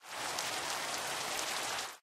rain5.ogg